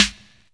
TM-88 Snare [Master].wav